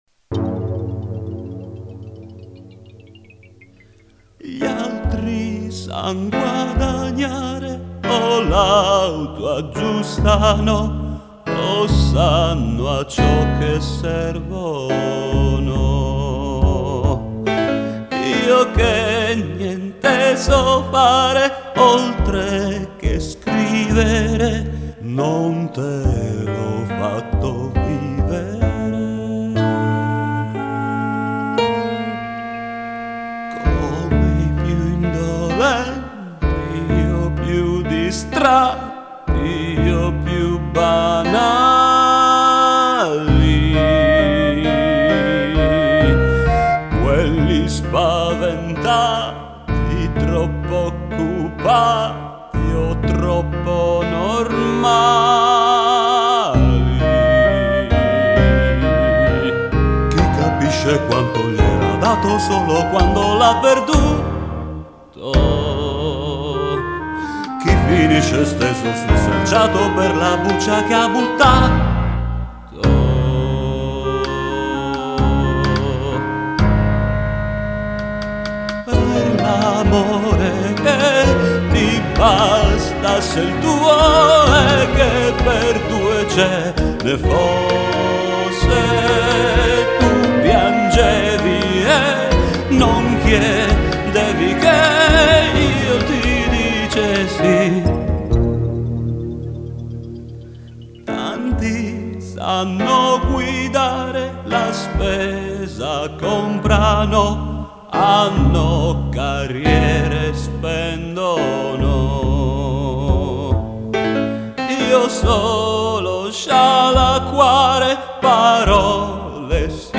PROVINI di canzoni (registrazioni casalinghe)